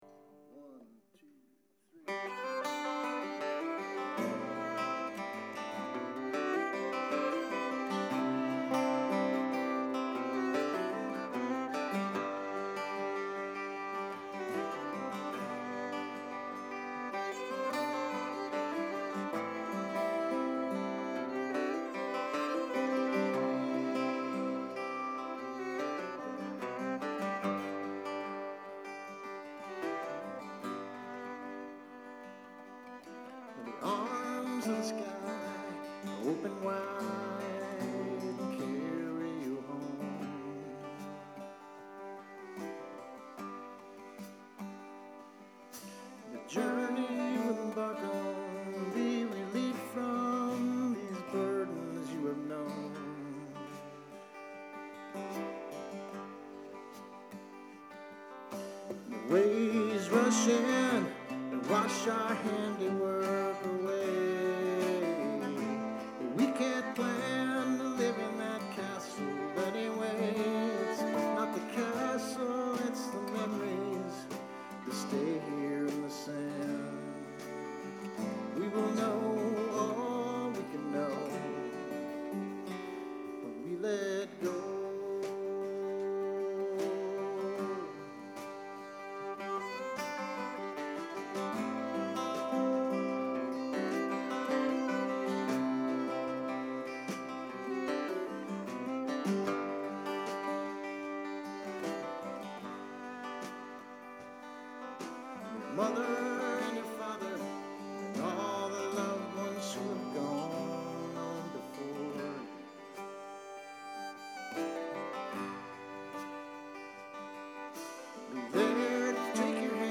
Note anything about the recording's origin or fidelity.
Two hour long sets with a rapt and appreciative audience.